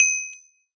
successful_hit.ogg